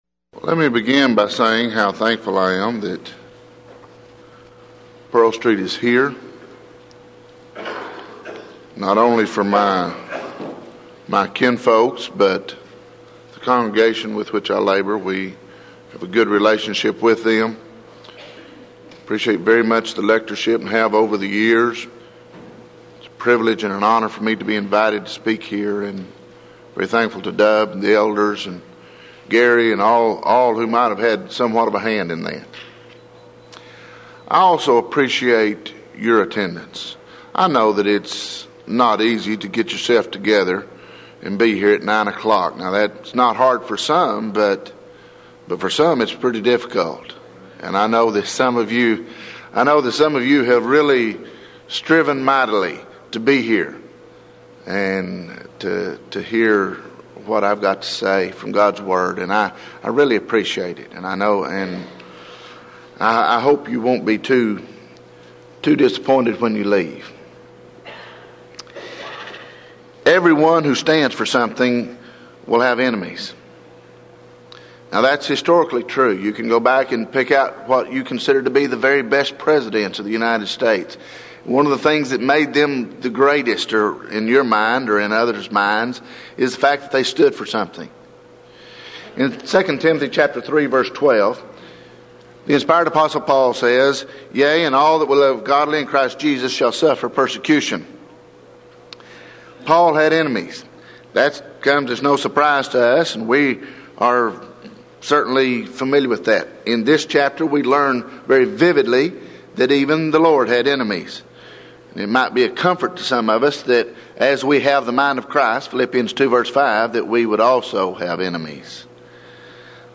Event: 1995 Denton Lectures